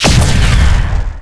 fire_no_cruise_disruptor.wav